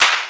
Snares
snr_32.wav